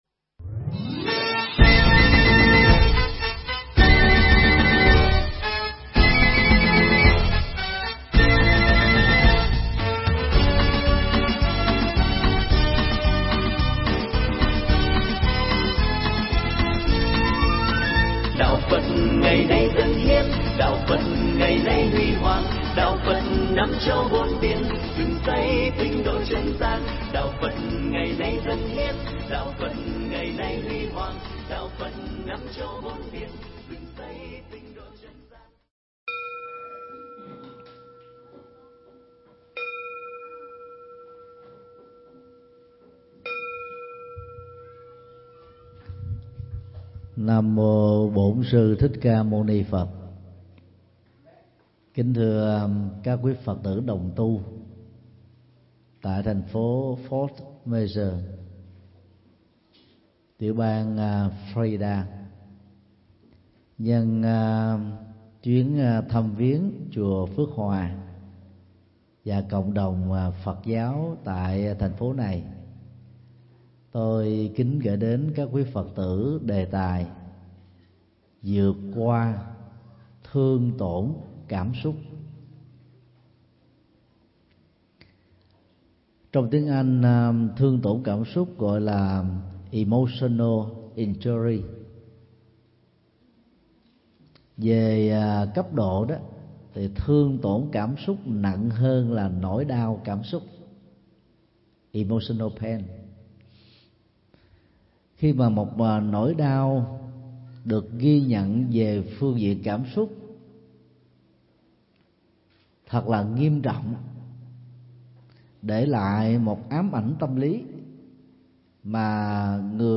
Mp3 Thuyết Giảng Vượt Qua Thương Tổn Cảm Xúc – Thượng Tọa Thích Nhật Từ giảng tại chùa Phước Hòa, Hoa Kỳ, ngày 5 tháng 6 năm 2017